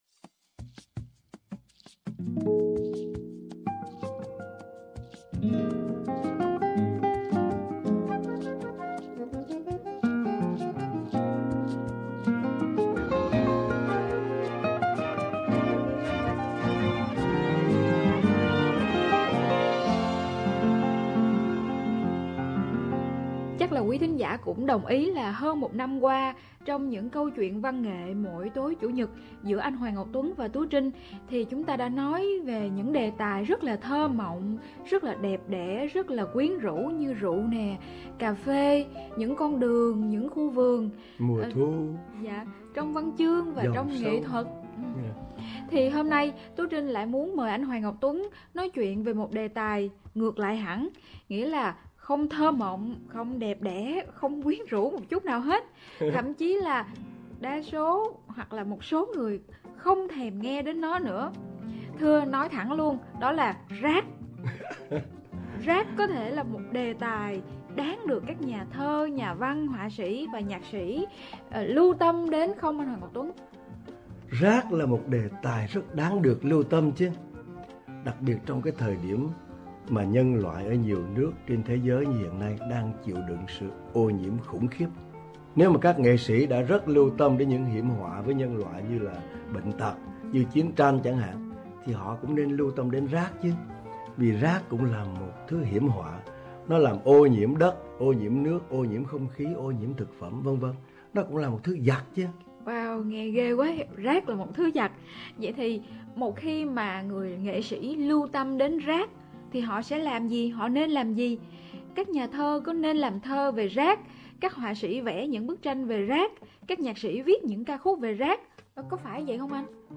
đã thực hiện những cuộc nói chuyện truyền thanh dài khoảng 15 phút vào mỗi đêm Chủ Nhật dưới hình thức phỏng vấn với nhà văn